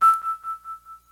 menu-confirmed.mp3